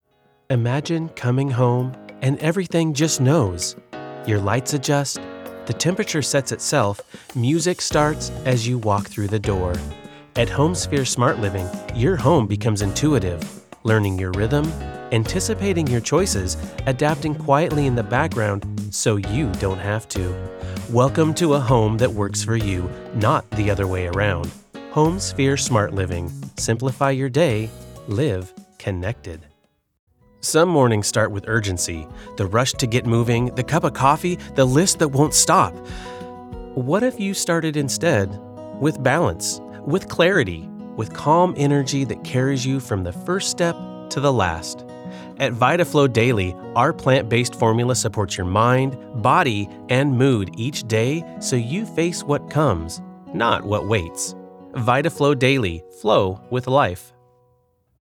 Voice Over Talent
Commercial Demo